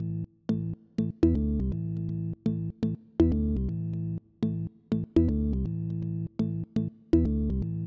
ORG Organ Riff A-E-D-C.wav